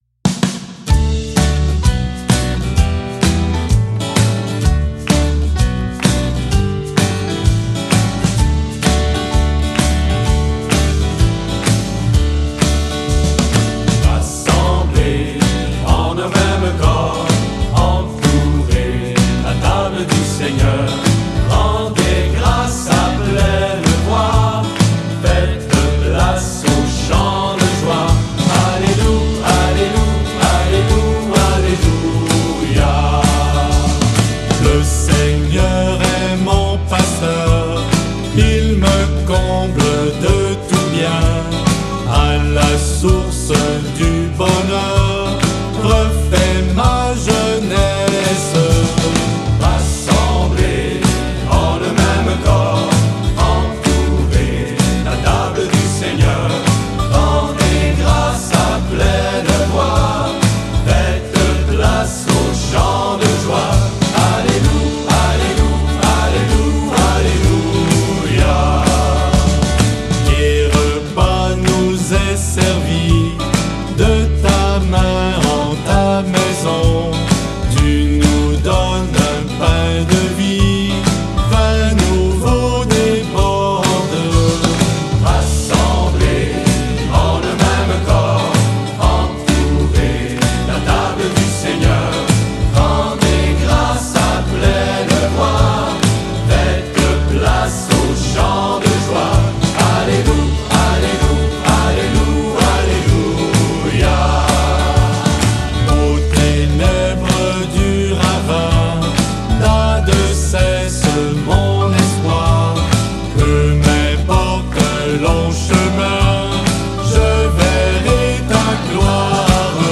2020 CHANTS D'ÉGLISE audio closed https